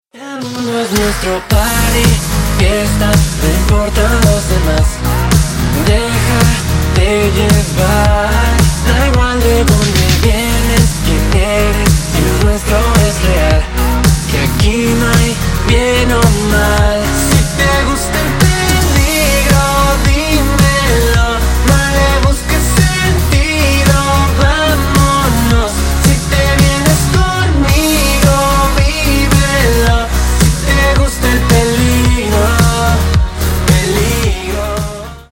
Латинские Рингтоны
Поп Рингтоны
Скачать припев песни